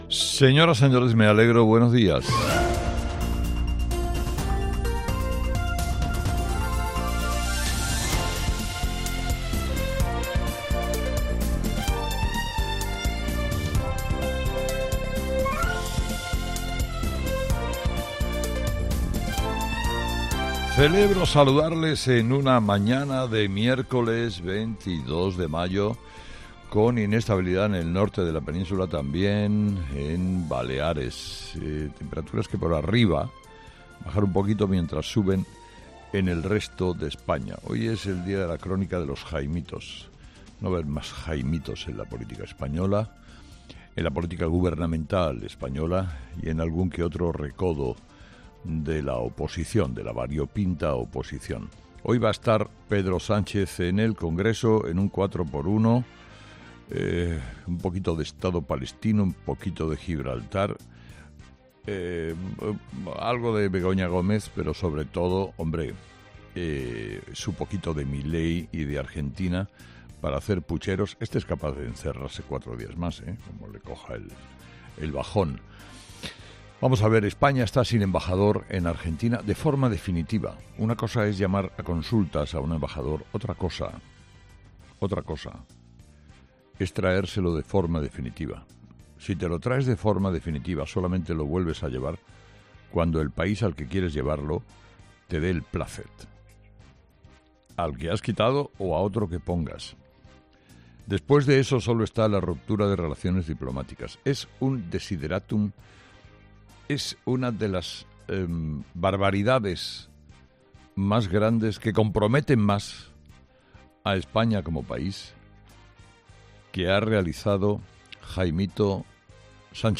Carlos Herrera, director y presentador de 'Herrera en COPE', comienza el programa de este miércoles analizando las principales claves de la jornada que pasan, entre otras cosas, por la declaración de Pedro Sánchez en el Congreso.